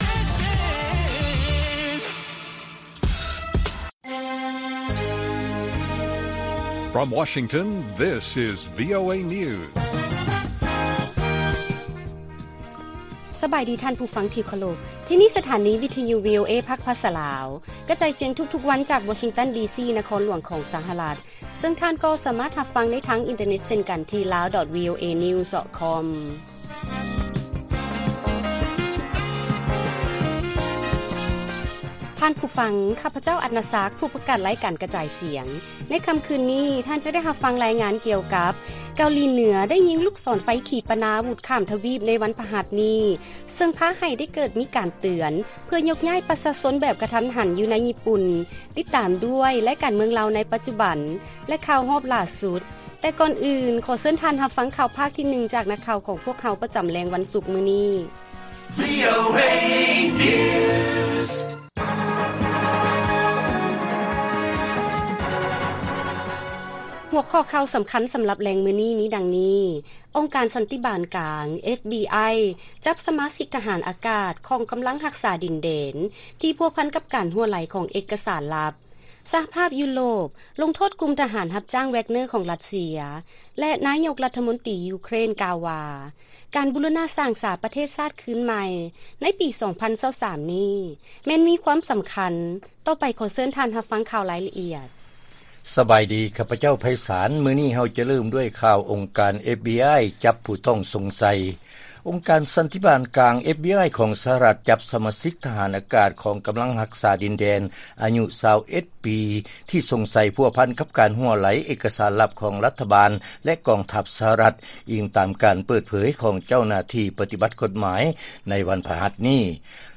ລາຍການກະຈາຍສຽງຂອງວີໂອເອ ລາວ: ອົງການ FBI ຈັບສະມາຊິກ ທະຫານອາກາດ ກຳລັງຮັກສາດິນແດນ ທີ່ພົວພັນກັບການຮົ່ວໄຫຼຂອງເອກກະສານລັບ